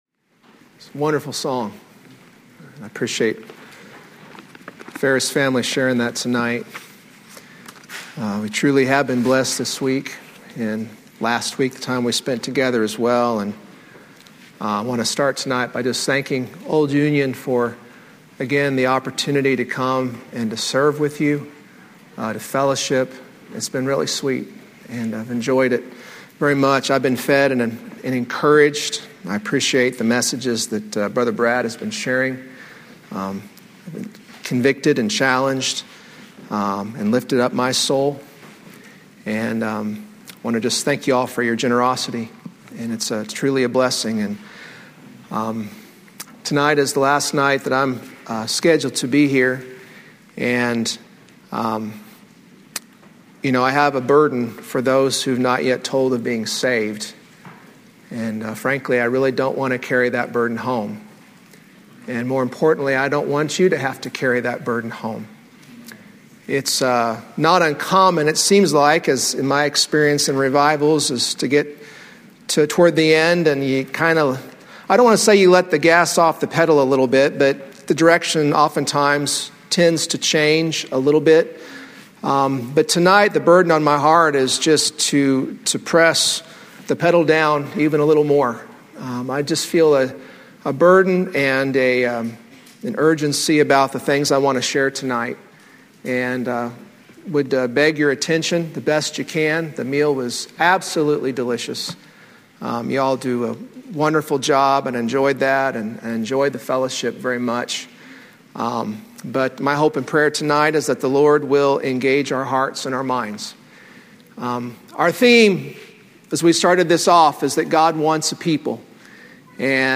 From Series: "Revival Sermons"
Sermons preached during special evening services - usually evangelistic.